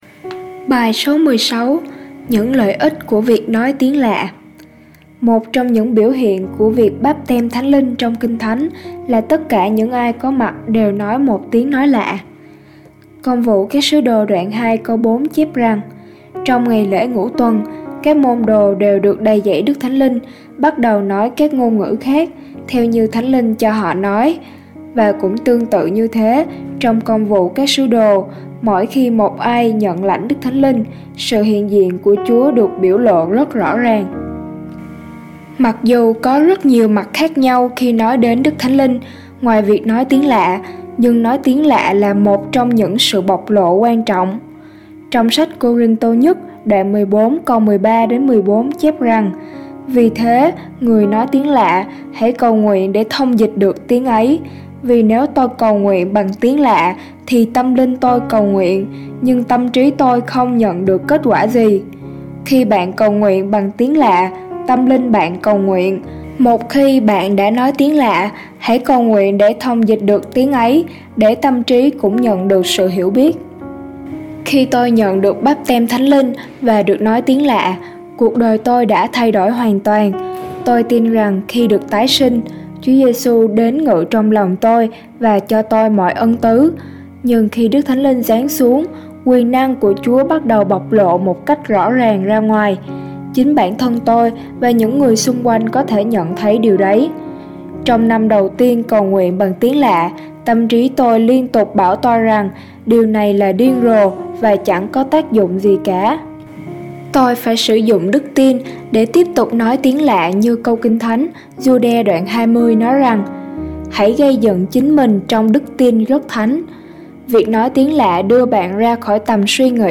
BÀI HỌC